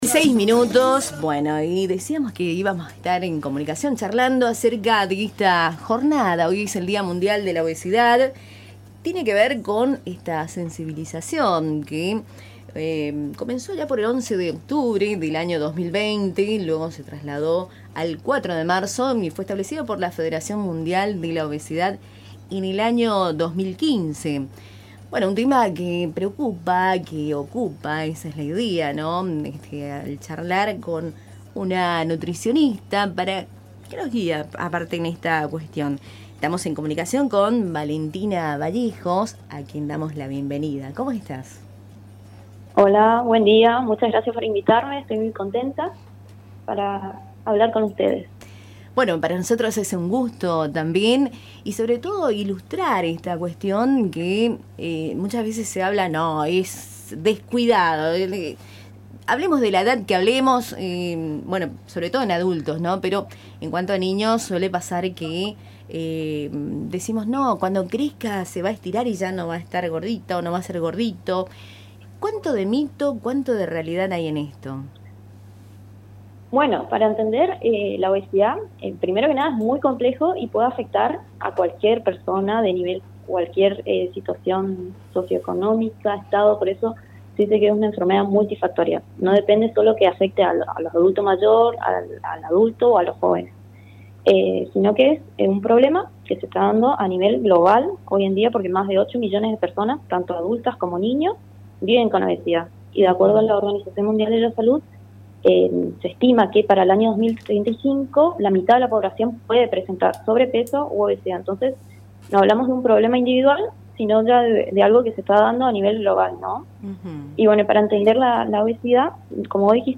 En comunicación con Cultura en Diálogo